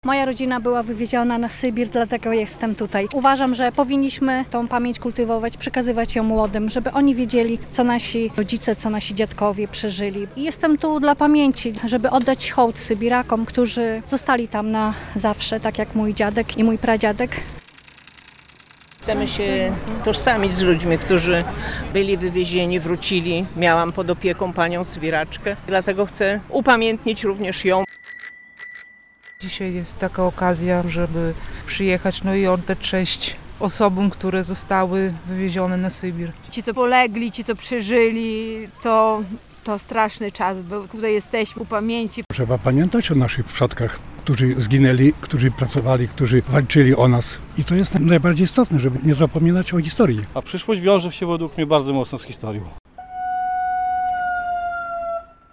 Ulicami Białegostoku przejechał rowerowy "Peleton Pamięci" - relacja